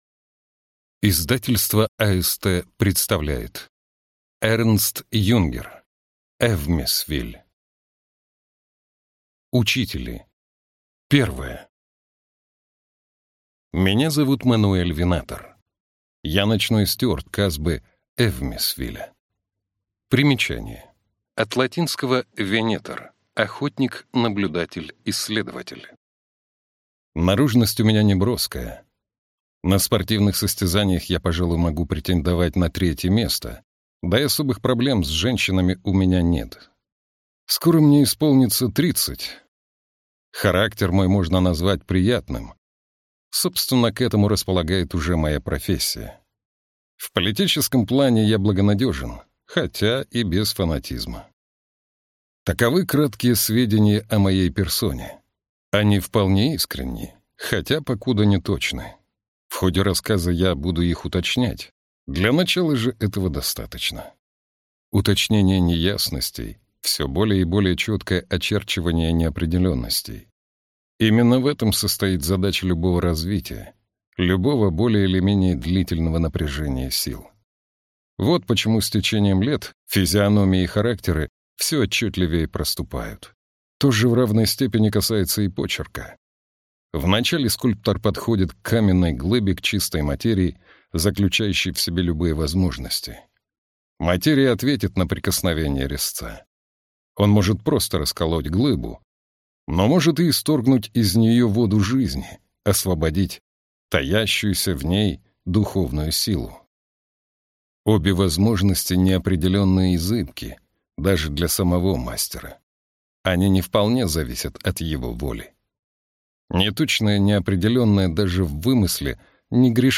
Аудиокнига Эвмесвиль | Библиотека аудиокниг